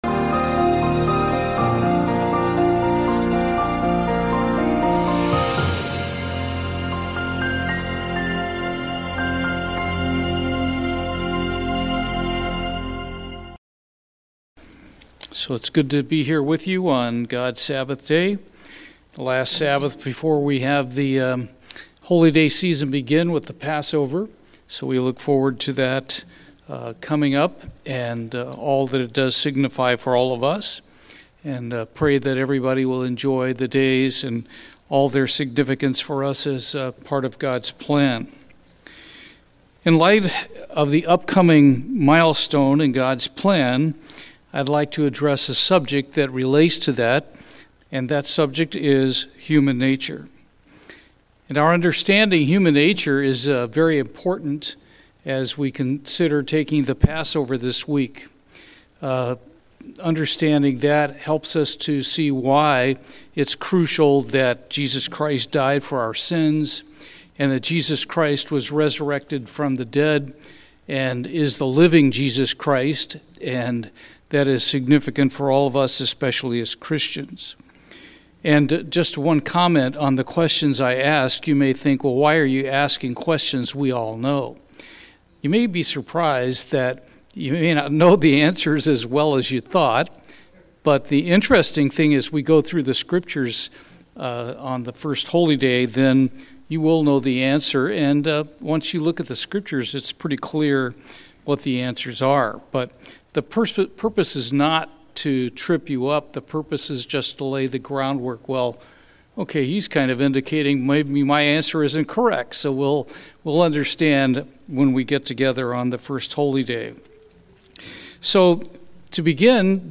In this sermon, we examine what is the carnal mind, how does it work, and how can we replace the carnal mind through the working of God's Holy Spirit.